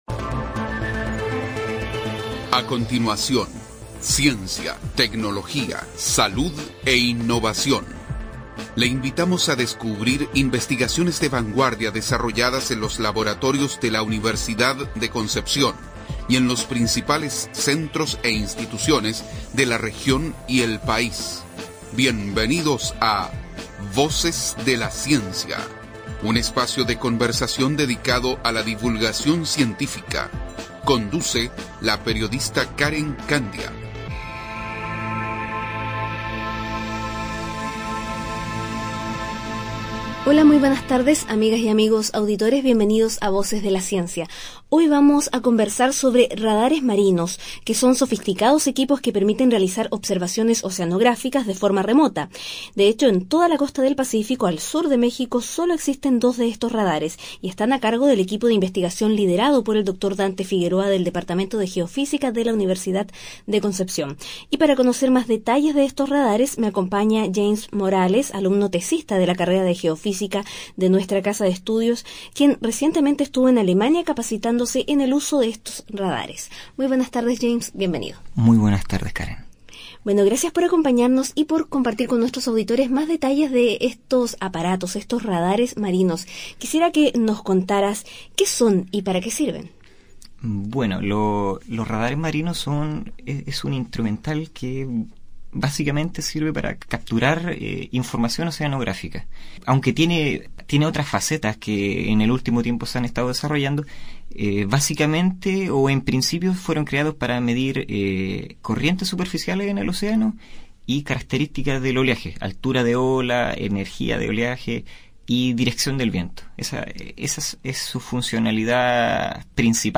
Programa emitido a las 13.30 hrs., del jueves 7 de agosto en la Radio Universidad de Concepción.